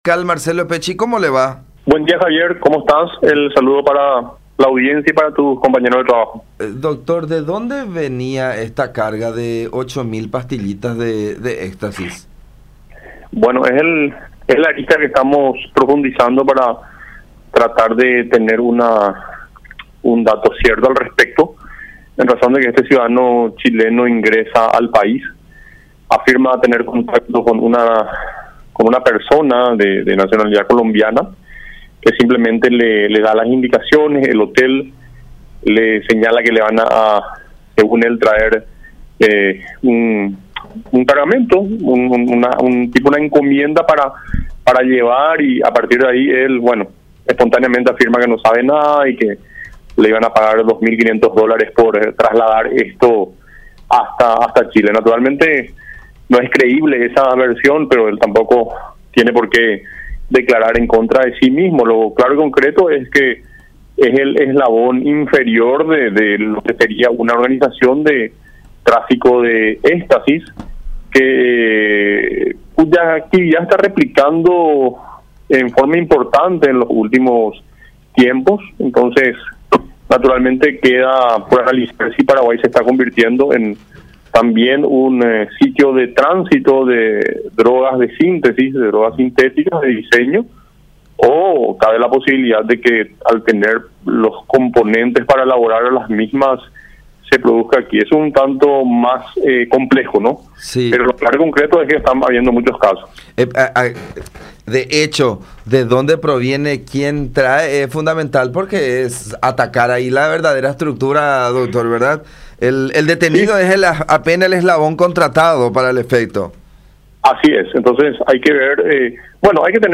“Este hombre es apenas un eslabón inferior. Hay que ver de dónde proviene la droga y quién le dio es clave. Dijo que la carga llevaba como una encomienda que le entregó una ciudadano colombiano”, expuso Marcelo Pecci, fiscal antidrogas, en contacto con La Unión.